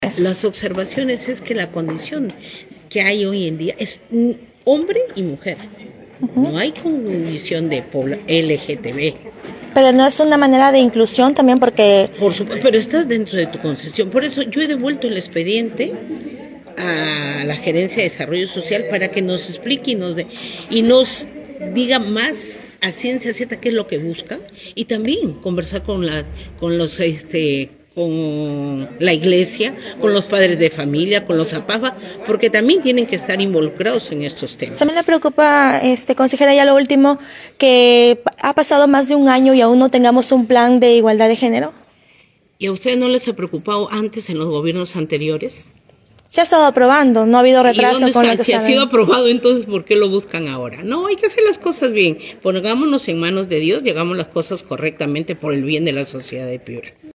Audio >> Rosa Seminario, consejera por Sullana